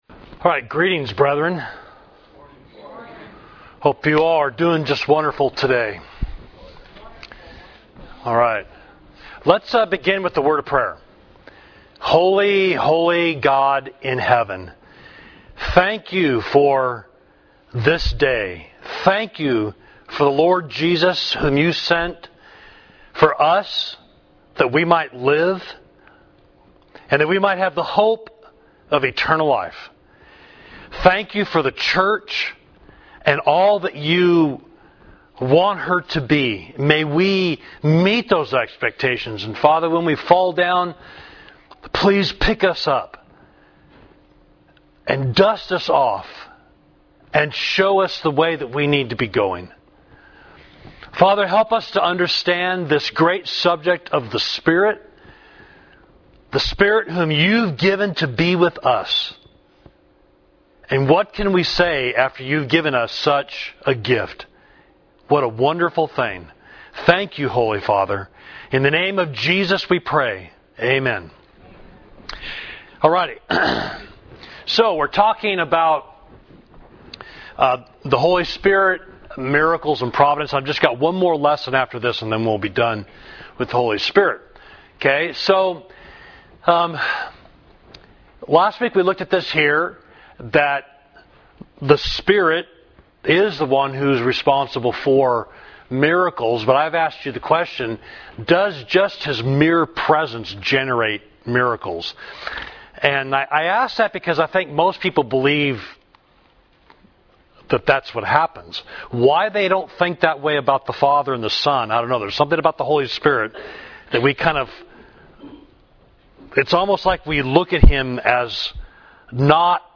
Class: The Holy Spirit—Miracles and Providence